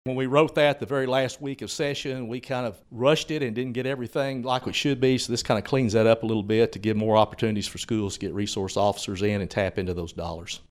CLICK HERE to listen to commentary from State Senator Dewayne Pemberton.